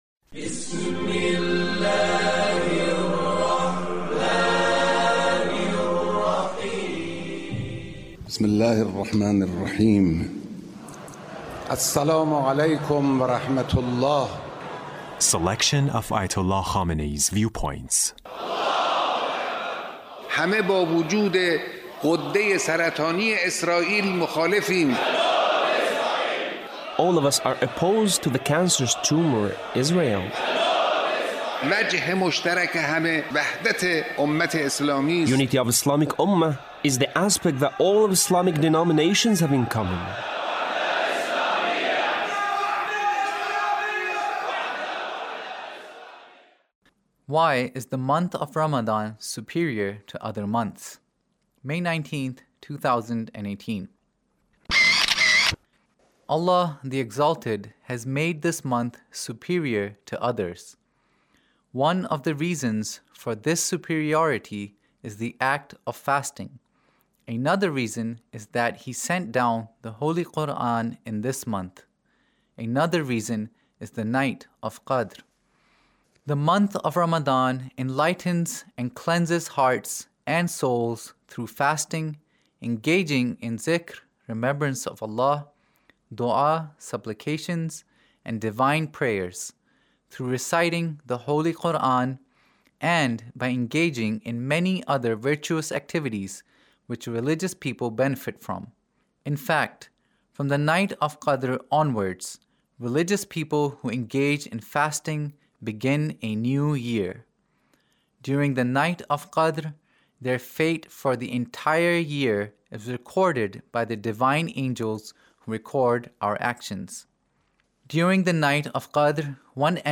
Leader's Speech On The Month of Ramadhan